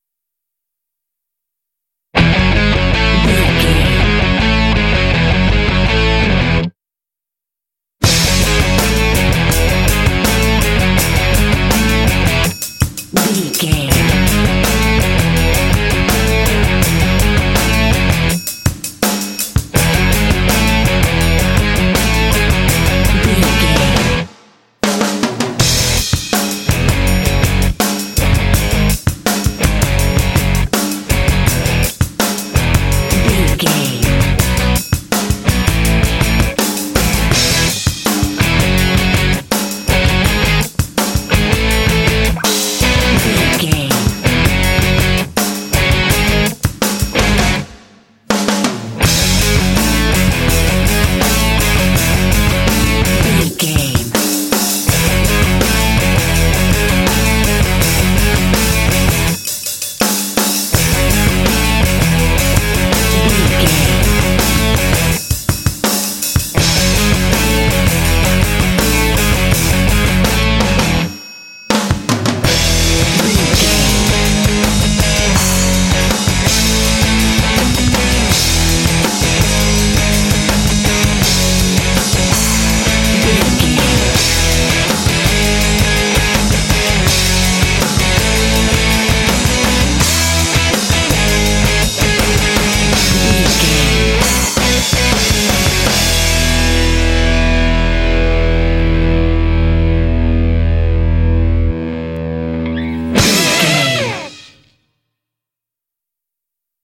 Ionian/Major
driving
powerful
energetic
heavy
drums
electric guitar
bass guitar
percussion
rock
heavy metal
classic rock